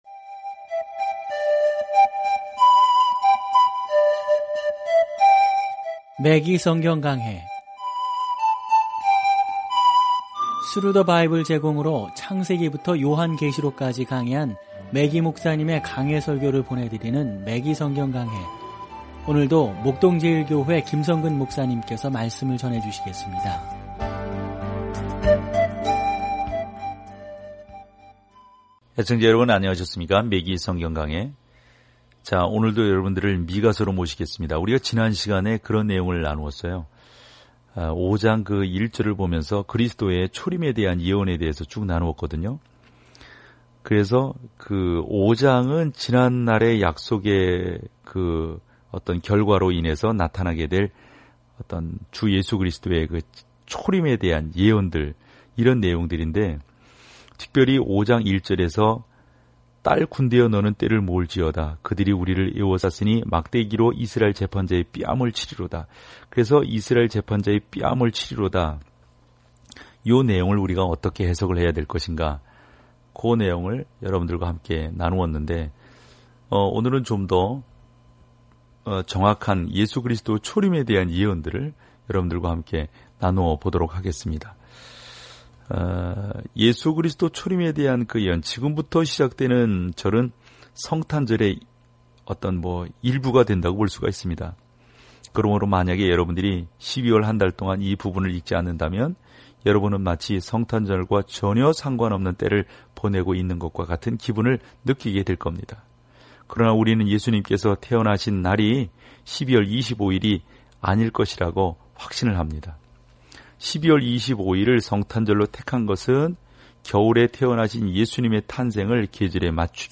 말씀 미가 5:2-5 10 묵상 계획 시작 12 묵상 소개 미가는 아름다운 산문으로 이스라엘과 유다의 지도자들에게 자비를 사랑하고 공의로 행하며 겸손히 하나님과 동행할 것을 촉구합니다. 오디오 연구를 듣고 하나님의 말씀에서 선택한 구절을 읽으면서 매일 미가를 여행하세요.